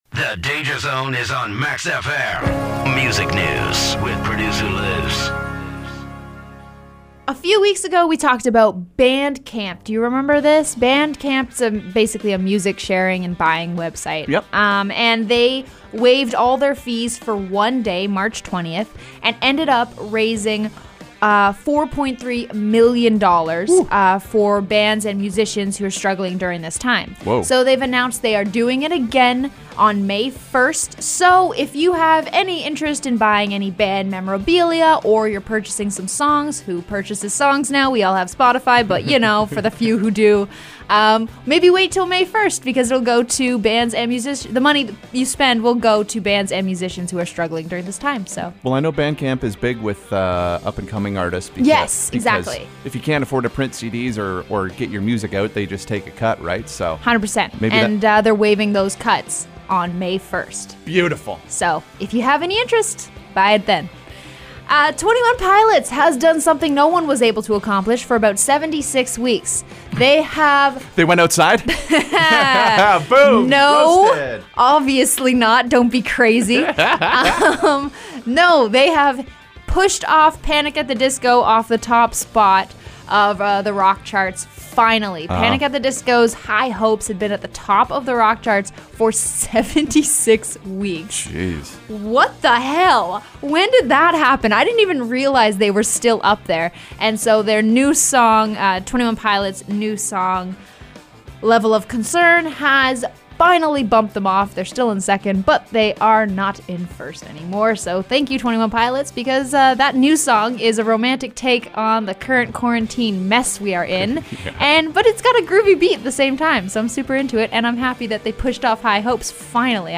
MUSIC-NEWS-APRIL-22.mp3